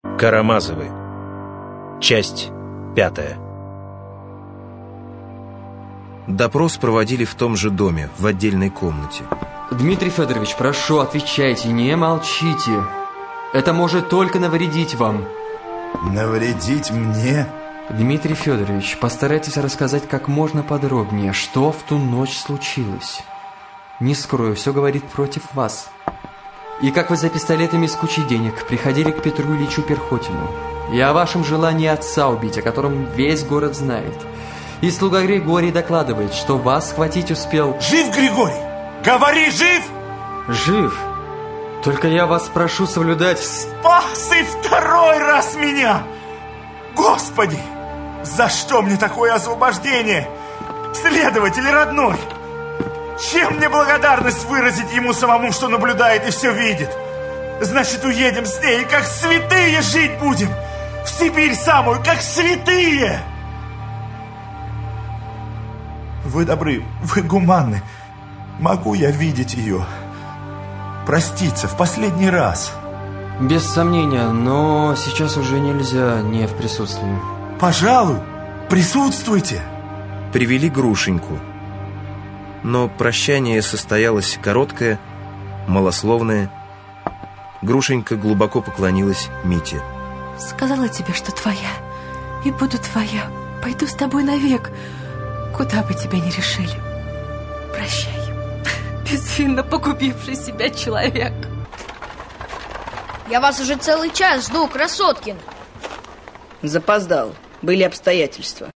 Аудиокнига Карамазовы (спектакль) 5-я серия | Библиотека аудиокниг